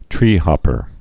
(trēhŏpər)